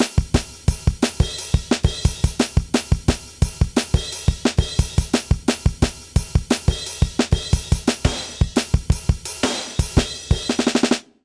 British ROCK Loop 175BPM.wav